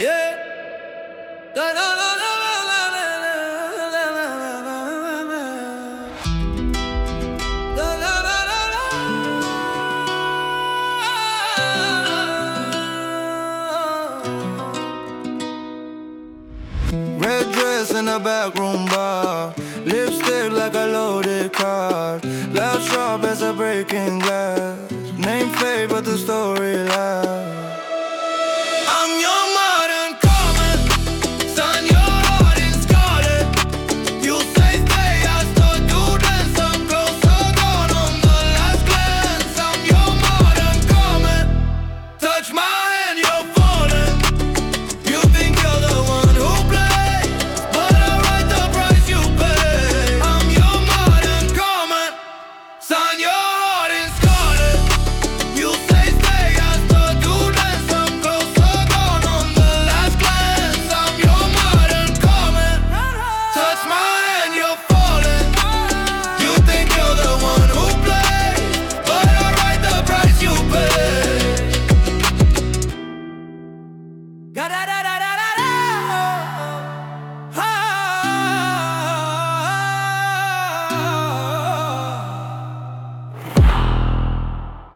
Instrumental / 歌なし
都会的で洗練された空気感が、選手の「大人っぽさ」と「かっこよさ」を際立たせます。